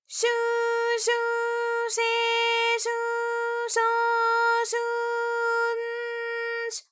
ALYS-DB-001-JPN - First Japanese UTAU vocal library of ALYS.
shu_shu_she_shu_sho_shu_n_sh.wav